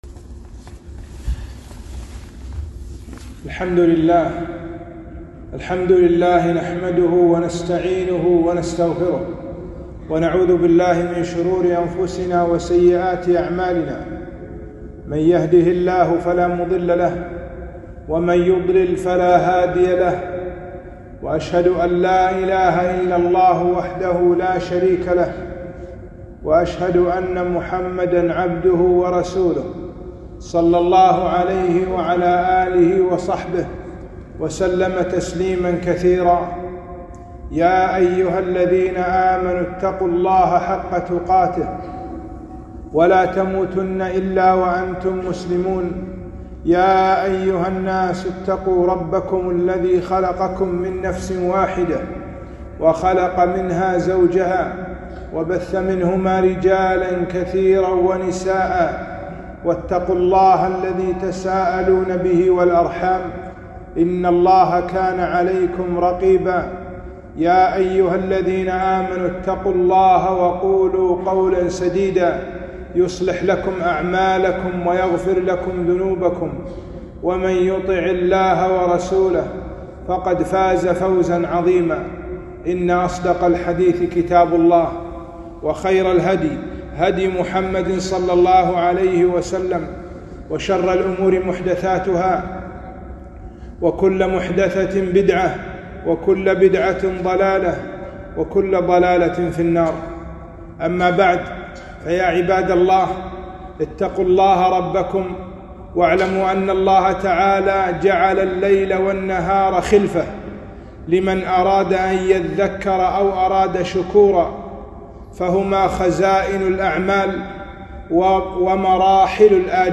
خطبة - استقبال العَشر الأخيرة